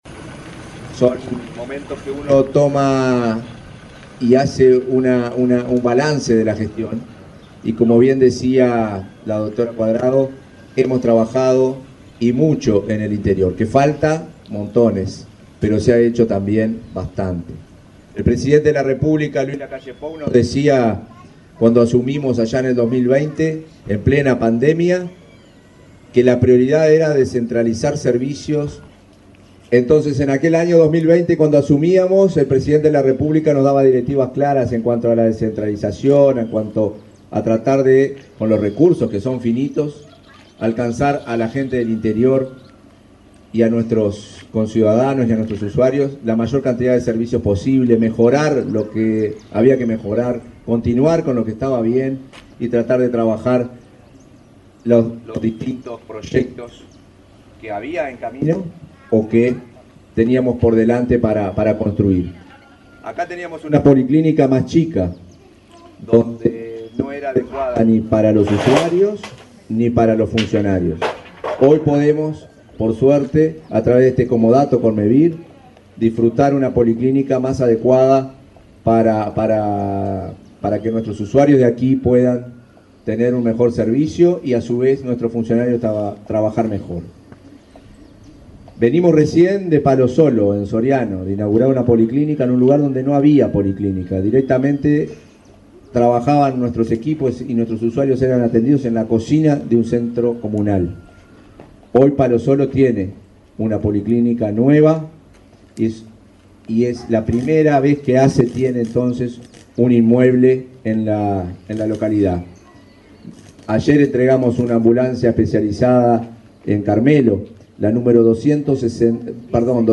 Palabras del presidente de ASSE, Marcelo Sosa
Palabras del presidente de ASSE, Marcelo Sosa 26/02/2025 Compartir Facebook X Copiar enlace WhatsApp LinkedIn El pasado martes 25, el presidente de la Administración de los Servicios de Salud del Estado (ASSE), Marcelo Sosa, encabezó la inauguración de una policlínica en Sarandí de Navarro, departamento de Río Negro.